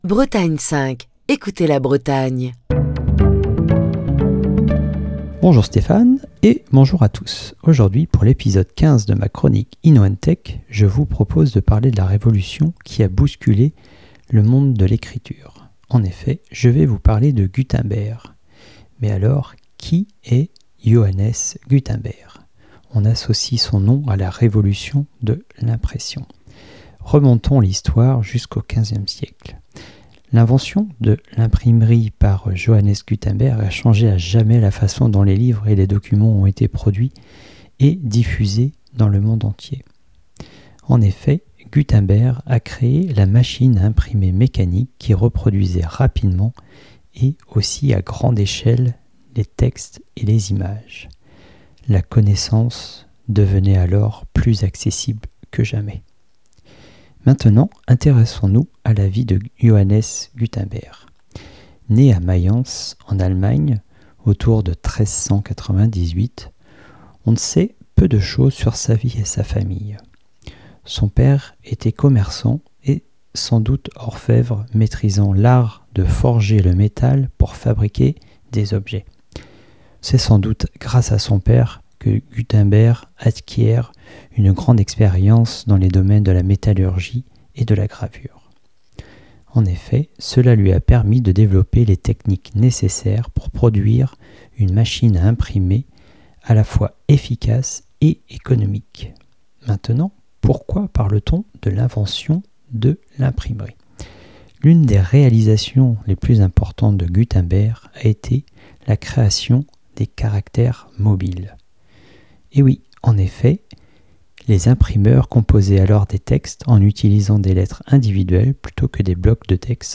Chronique du 10 mai 2023.